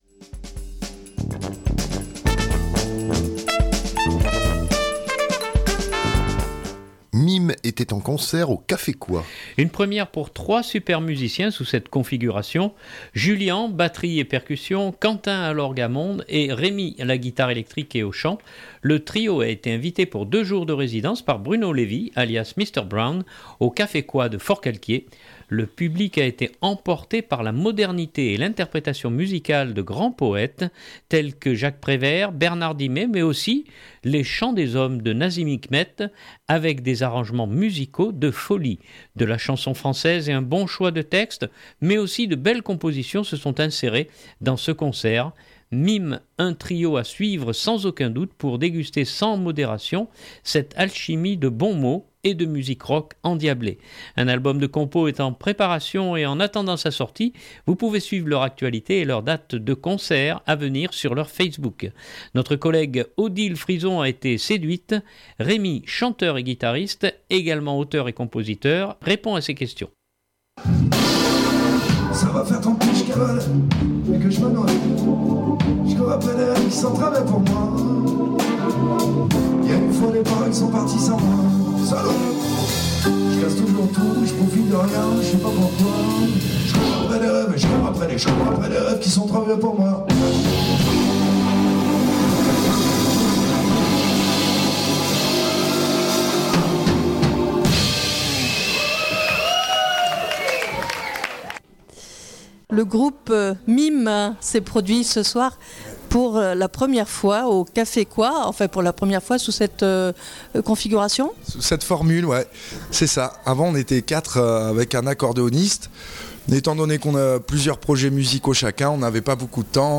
De la chanson française et un bon choix de textes, mais aussi de belles compositions se sont insérées dans ce concert. MimE, un trio à suivre sans aucun doute, pour déguster sans modération cette alchimie de bons mots, et de musique rock endiablée.